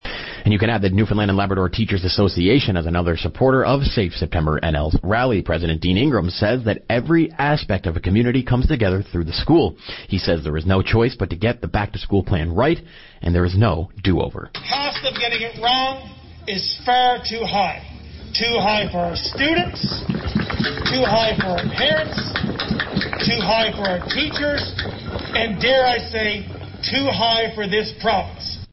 Media Interview - VOCM 7am News Sept 2, 2020